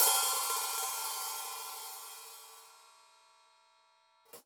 Index of /musicradar/Hi Hats/Sabian B8
CYCdh_Sab_OpHat-07.wav